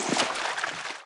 Divergent / mods / Footsies / gamedata / sounds / material / human / step / t_water1.ogg
t_water1.ogg